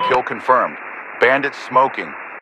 Radio-pilotKillAir4.ogg